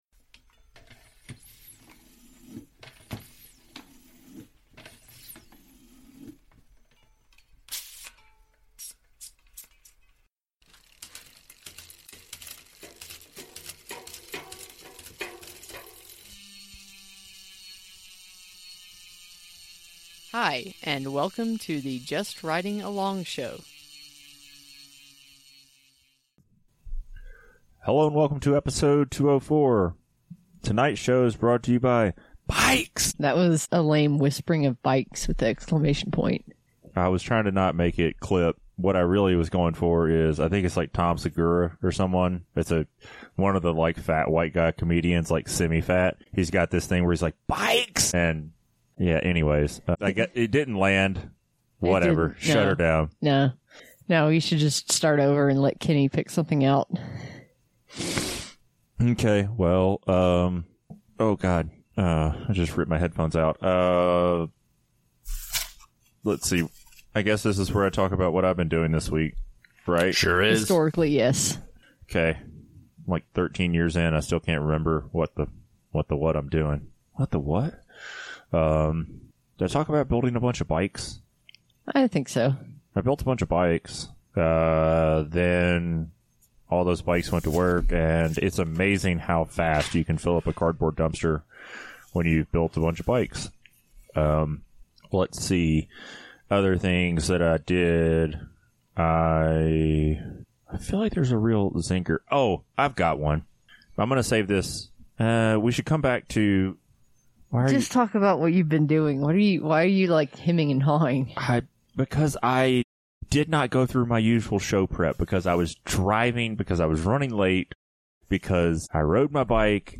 Listen in for entertaining conversation between long-time bike industry folks. We dish out our knowledge peppered with humor, rants, and strong opinions.